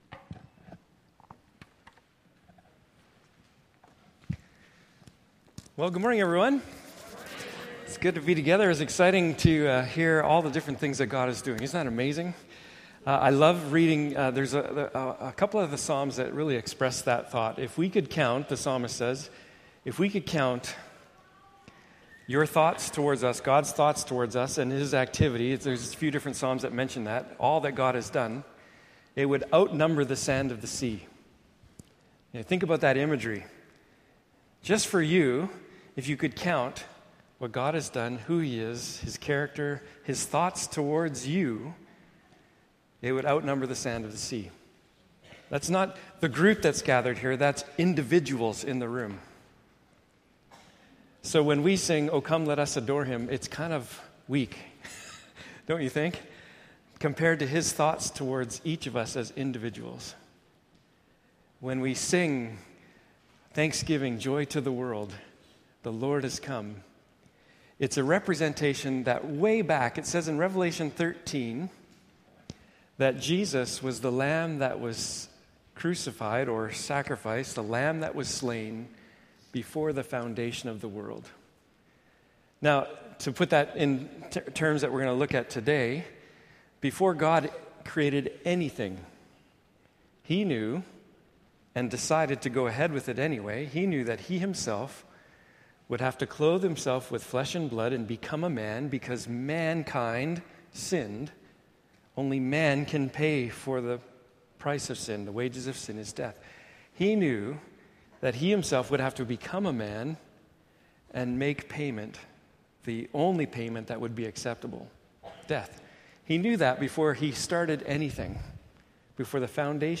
Passage: Esther 8:1-9:19 Service Type: Morning Service